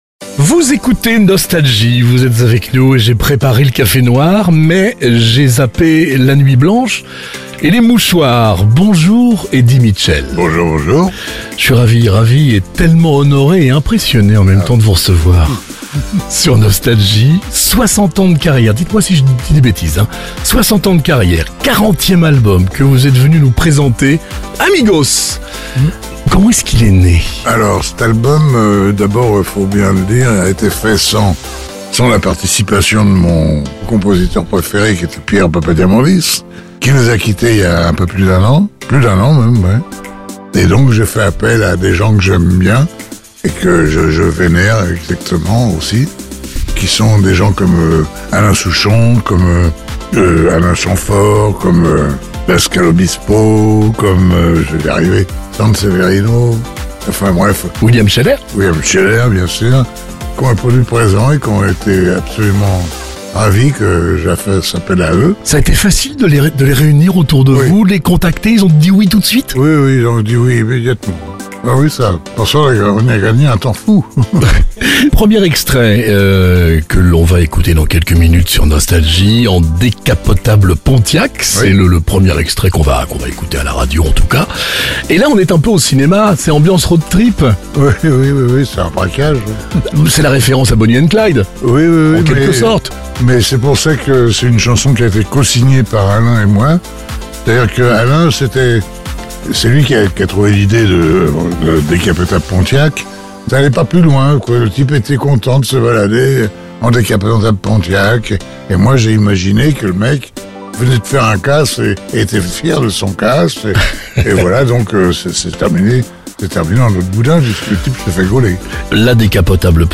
Eddy Mitchell est l’invité de Nostalgie et présente son nouvel album " Amigos "